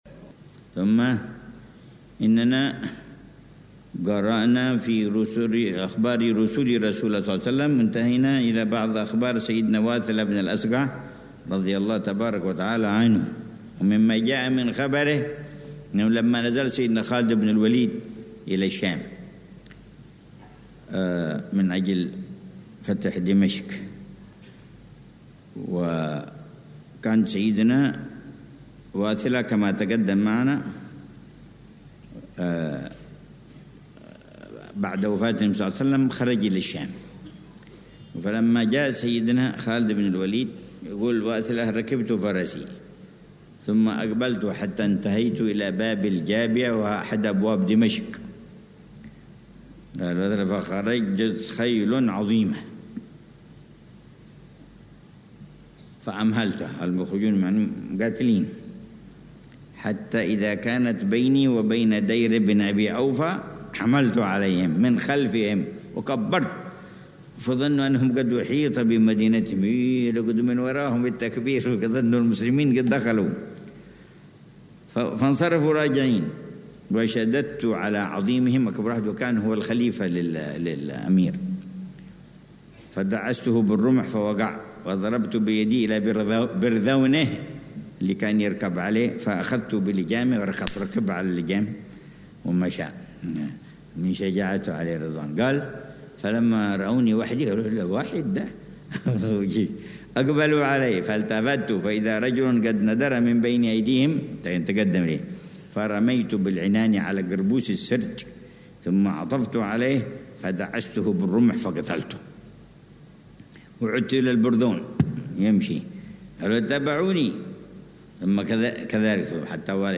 درس السيرة النبوية - رُسُل رسول الله ﷺ: واثلة بن الأسقع - 4 - قيس بن نمط الهمداني
من دروس السيرة النبوية التي يلقيها العلامة الحبيب عمر بن محمد بن حفيظ، ضمن دروس الدورة التعليمية الحادية والثلاثين بدار المصطفى بتريم للدراسات